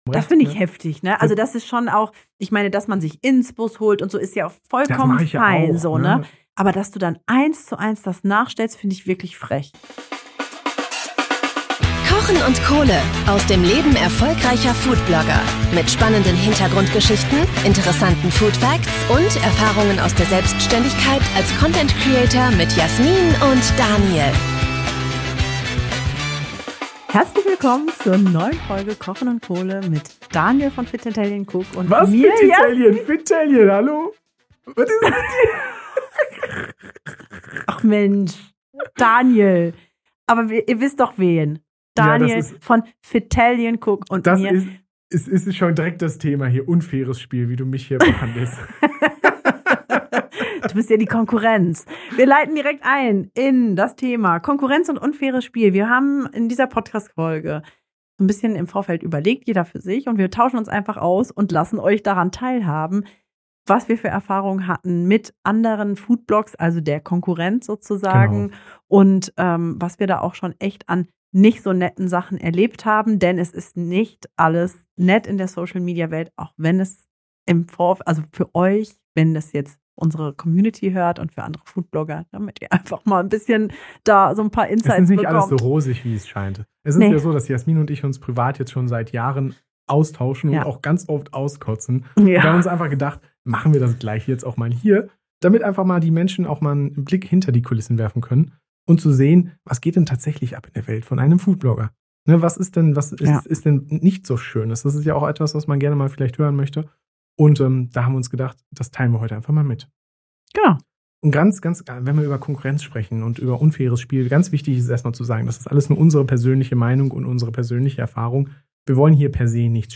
In dieser Folge sind wir in Plauderstimmung und nehmen euch mit hinter die Kulissen der Foodblogging-Szene. Wir sprechen offen darüber, wo wir schon enttäuscht wurden, welche unfaire Konkurrenz uns begegnet ist und wie sich der Druck auf Social Media anfühlt. Es geht um die dunklen Seiten des Business, aber auch darum, wie wir damit umgehen und was wir daraus gelernt haben.